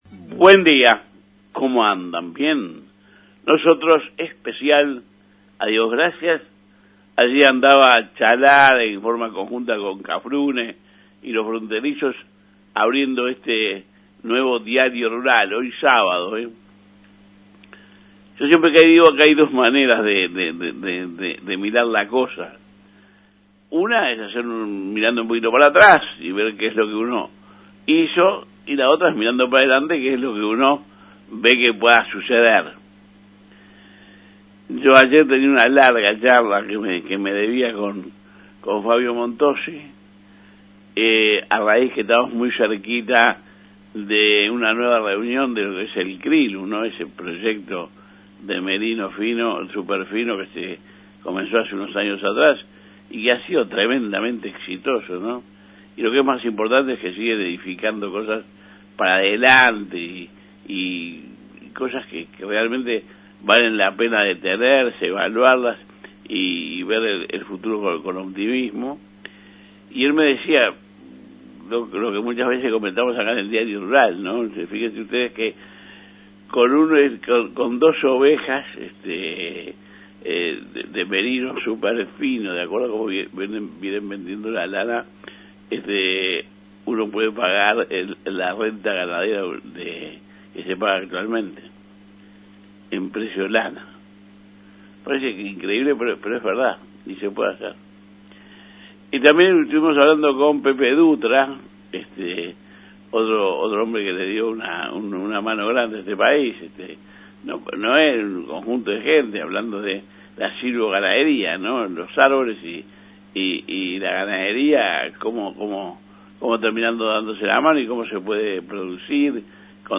EDITORIAL COMPLETO